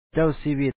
Pronunciation Notes 20
càw síwīt King